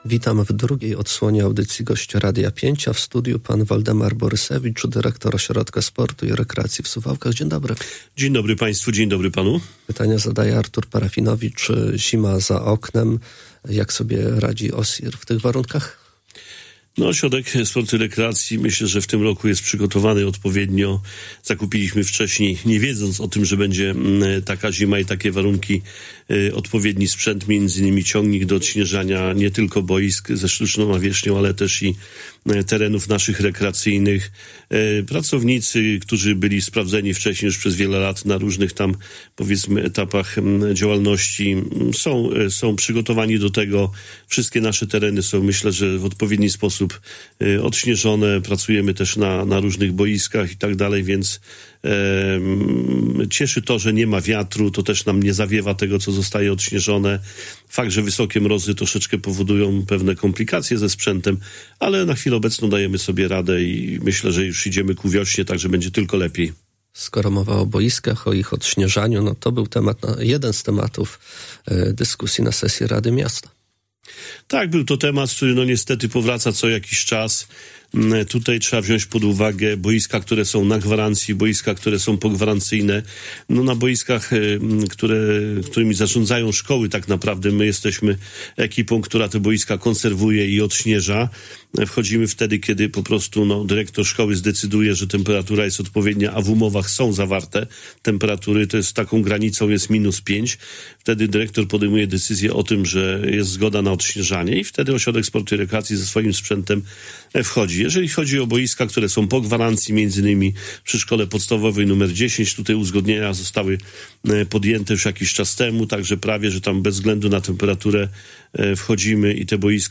Poniżej całą rozmowa: https